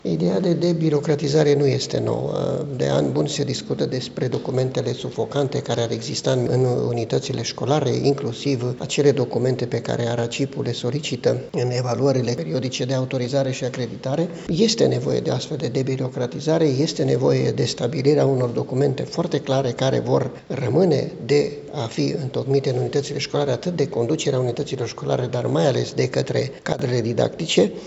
Inspectorul școlar general al județului Mureș, Ioan Macarie, a arătat că propunerea este veche, dar că nu se vor putea elimina complet hârtiile de completat pentru dascăli.